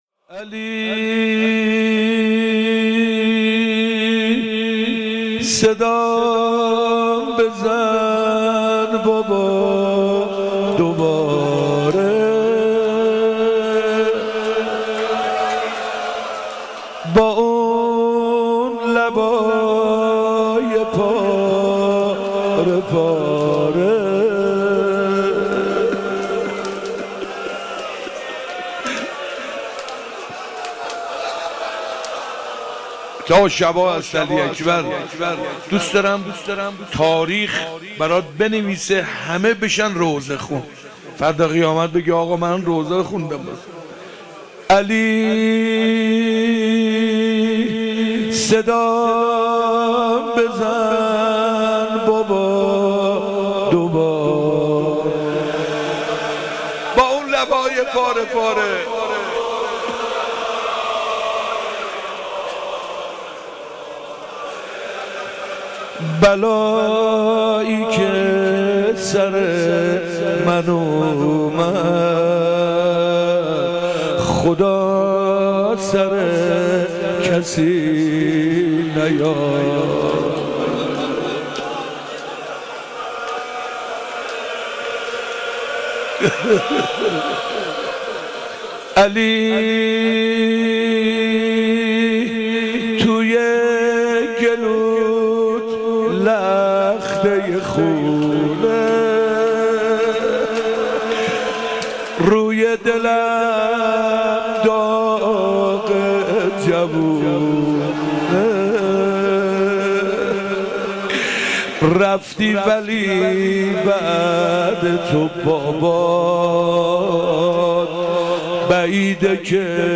شب هشتم محرم 97
زمزمه - علی صدام بزن بابا